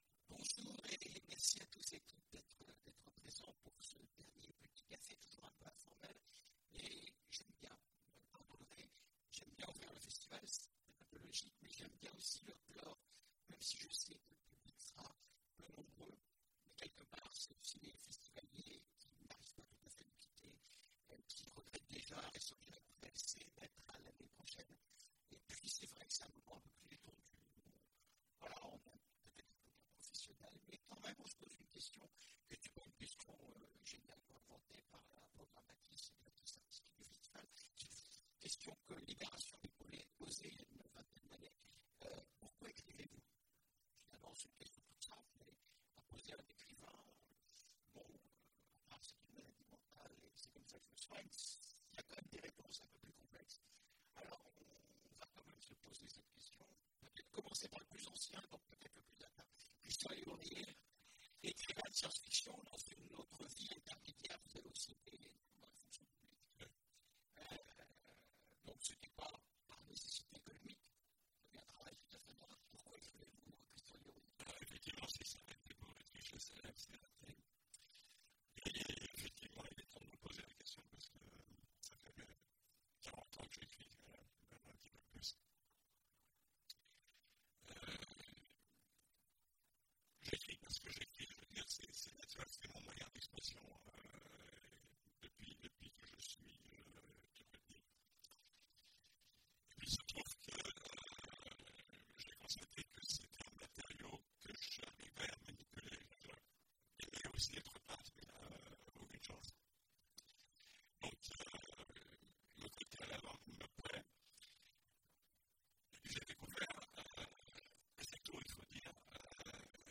Imaginales 2016 : Conférence Un dernier pour la route !